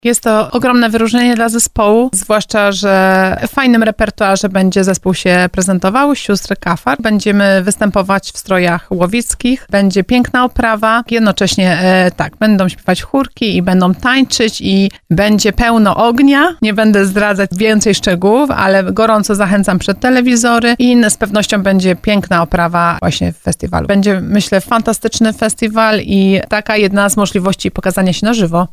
gościła na antenie RDN Małopolska